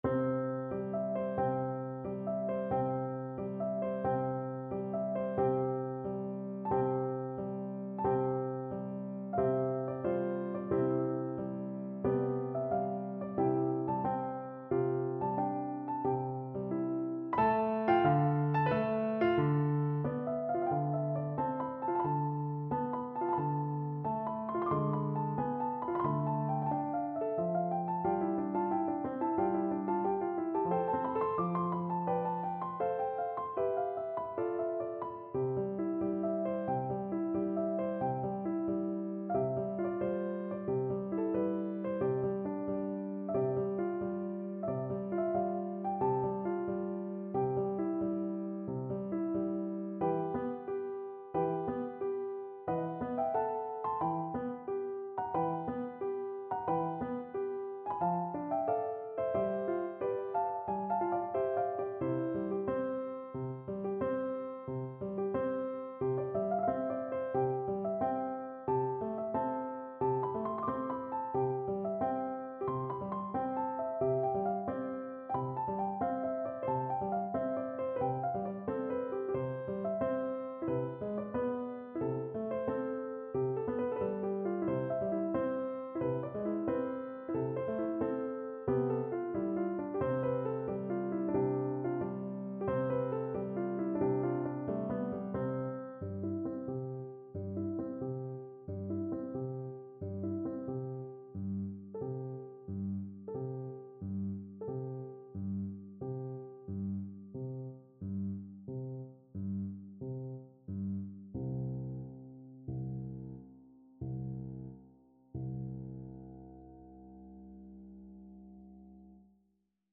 Moderato =90
Classical (View more Classical Flute Music)